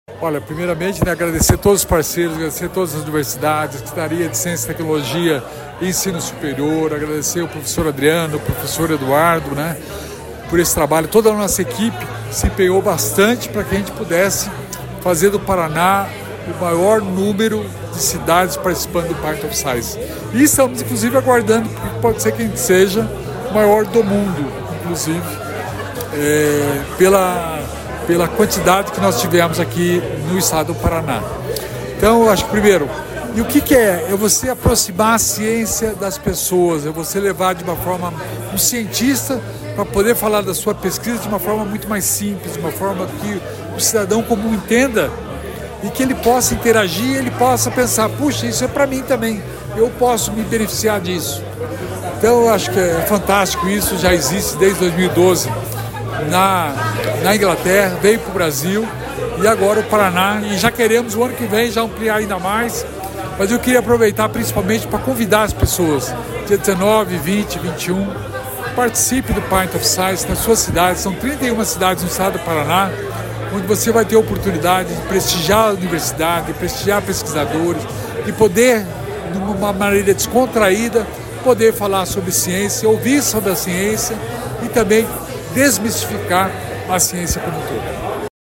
Sonora do secretário da Inovação e Inteligência Artificial, Alex Canziani, sobre o início do festival Pint Of Science